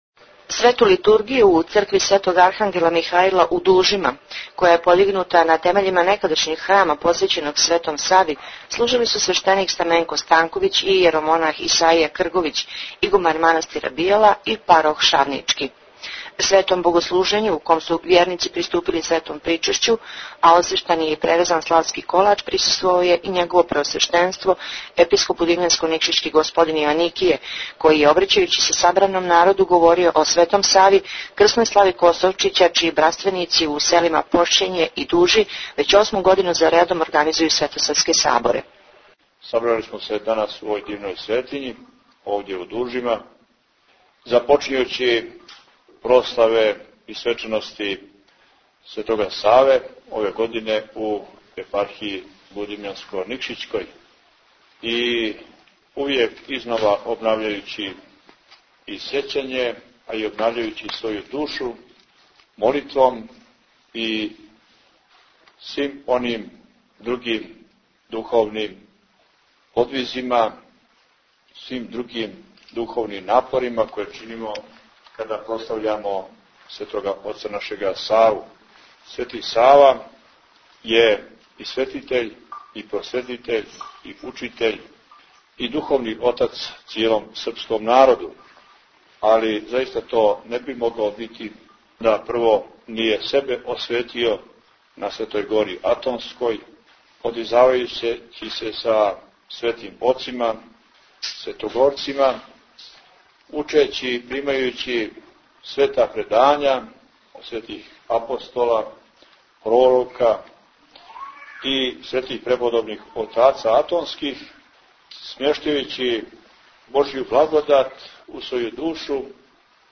Tagged: Извјештаји Наслов: Duhovne akademije u slavu Svetog Save Албум: izvjestaji Година: 2009 Величина: 22:35 минута (3.88 МБ) Формат: MP3 Mono 22kHz 24Kbps (CBR) Светом Литургијом и традиционалним црквено - народним сабором братстава Косовчић, који је у суботу 24. јануара одржан у селу Дужи, код Шавника почеле су духовне академије у славу Светог Саве, које ће се наредних дана одржавати у Епархији будимљанско - никшићкој и широм Црне Горе.